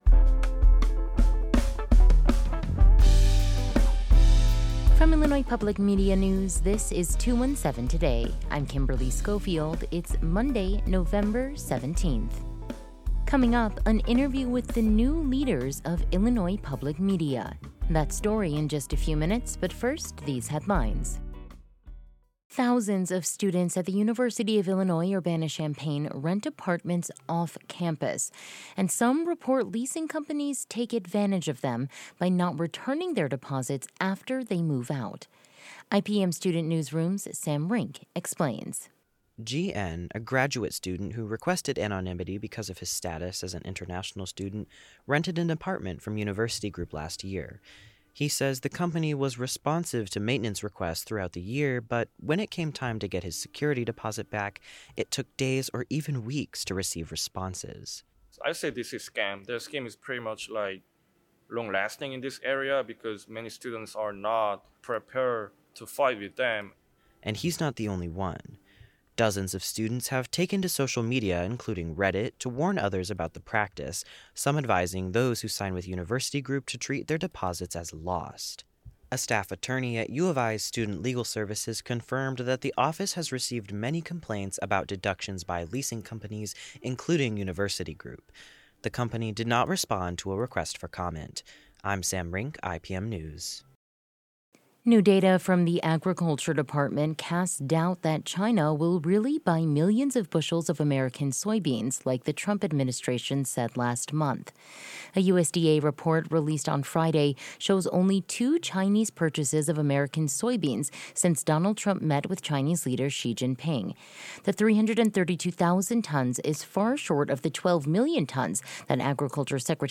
In today’s deep dive, an interview with The New Leaders of Illinois public media.